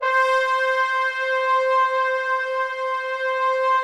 instruments / brass / trombone-section / samples / C5.mp3
C5.mp3